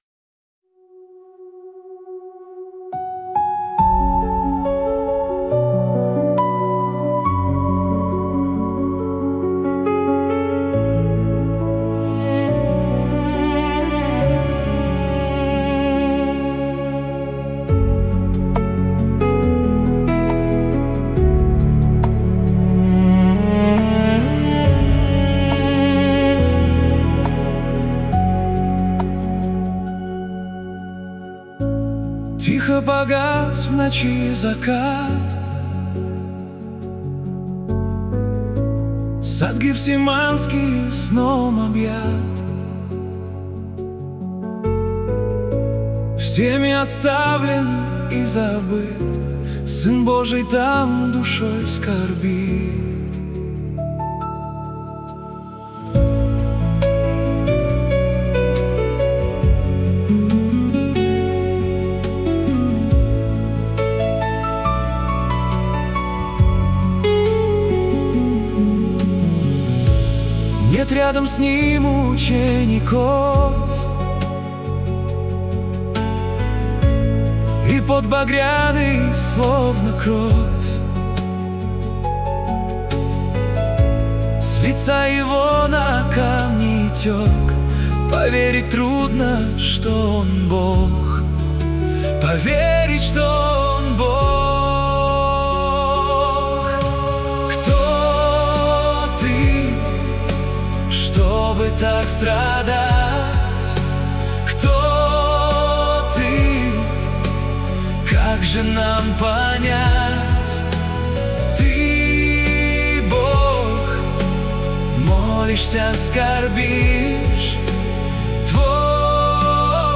песня ai
14 просмотров 27 прослушиваний 4 скачивания BPM: 78